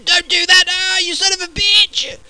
home *** CD-ROM | disk | FTP | other *** search / Horror Sensation / HORROR.iso / sounds / iff / dontdoit.snd ( .mp3 ) < prev next > Amiga 8-bit Sampled Voice | 1992-12-21 | 51KB | 1 channel | 22,095 sample rate | 2 seconds